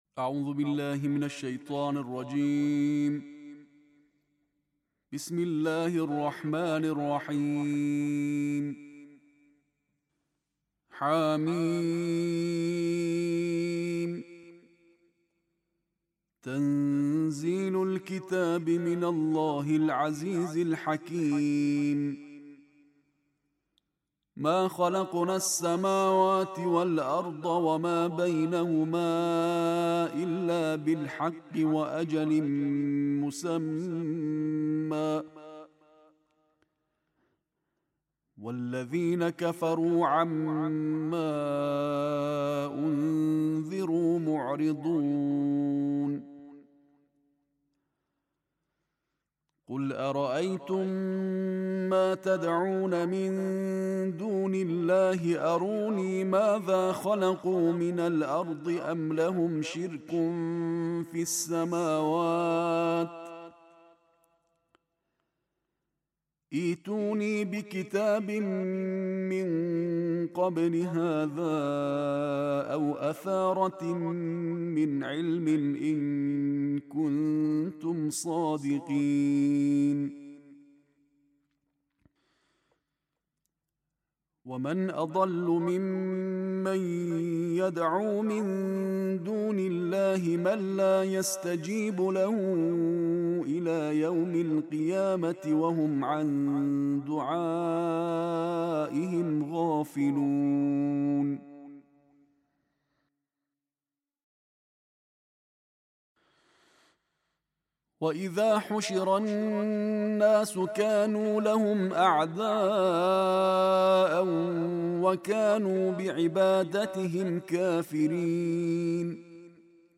在拉麦丹斋月第二十六天